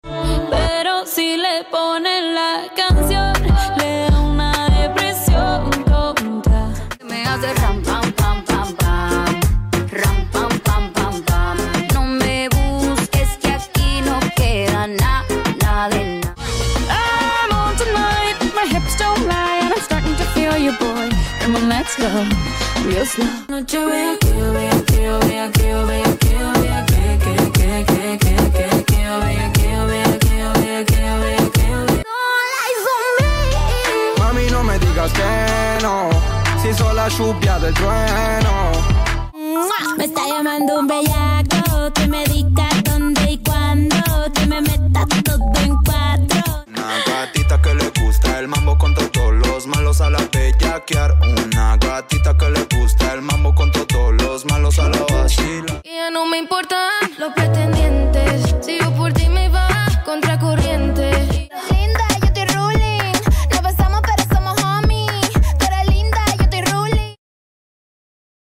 Dance, vibe, and let the rhythm take over.